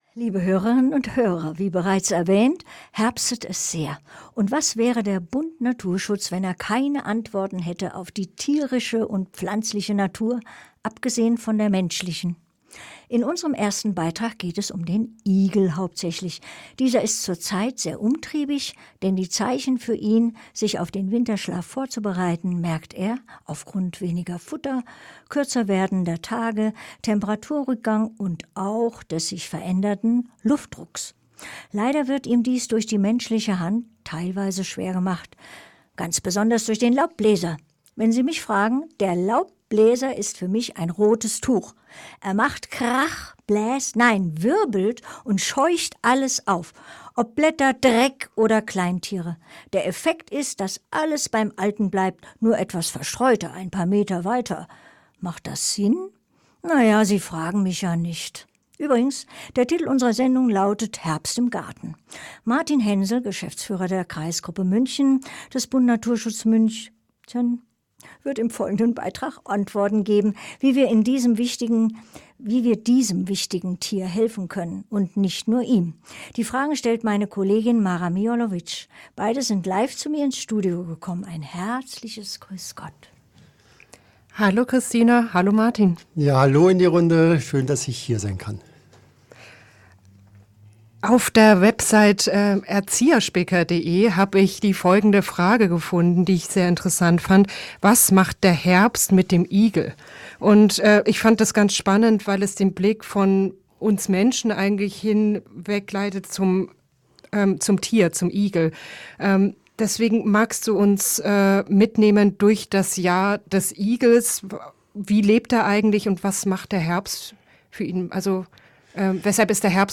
Interview 1